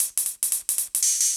Index of /musicradar/ultimate-hihat-samples/175bpm
UHH_ElectroHatD_175-01.wav